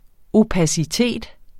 Udtale [ opasiˈteˀd ]